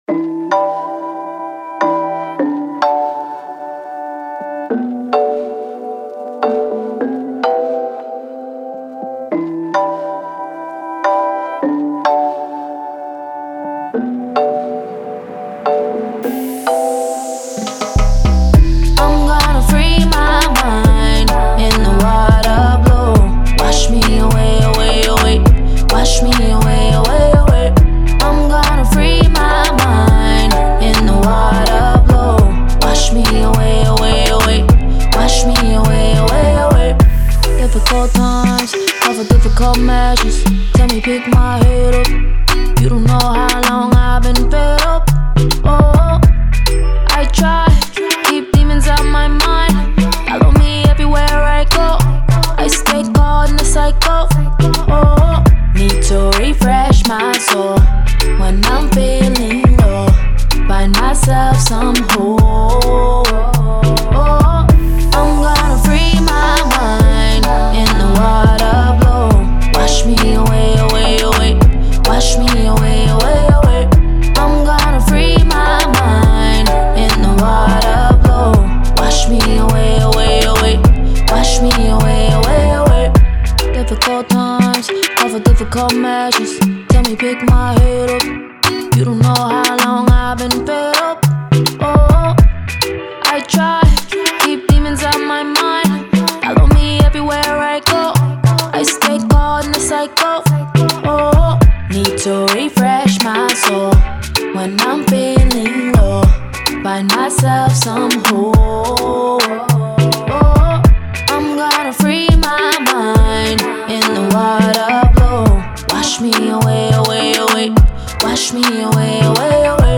Reggae, Afrobeat
E min